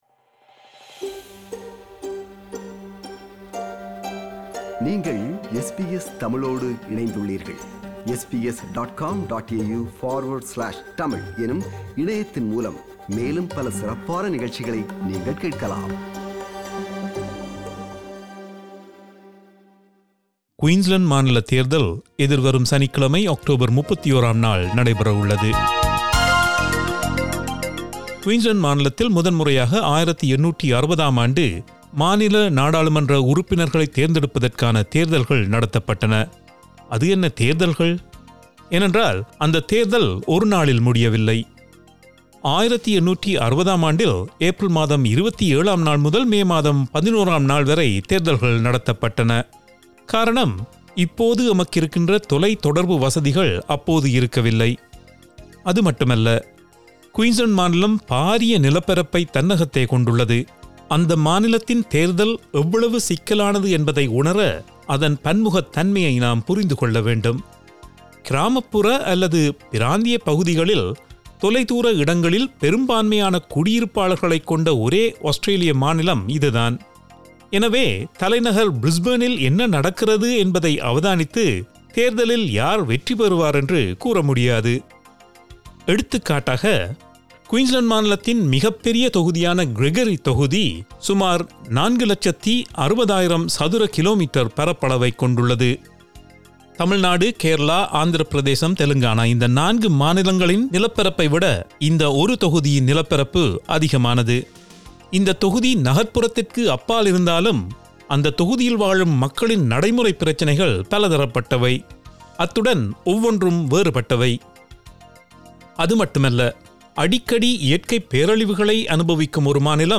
விவரணம்